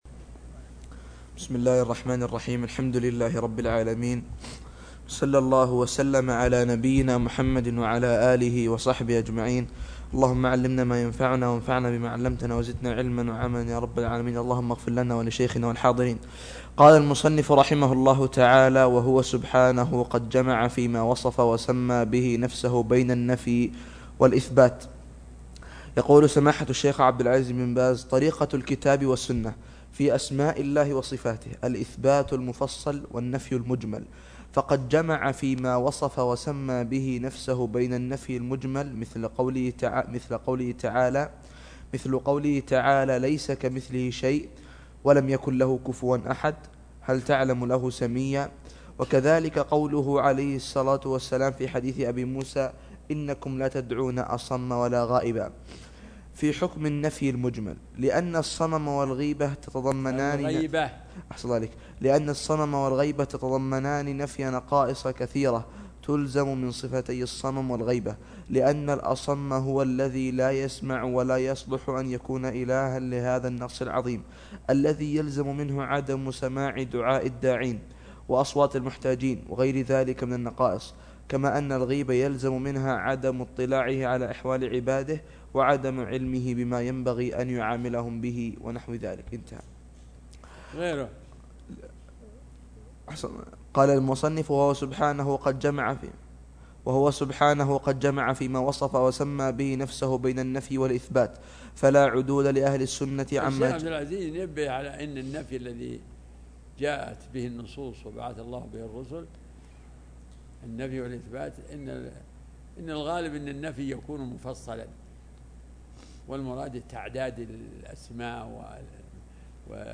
العقيدة الإسلامية      شروح كتب عقيدة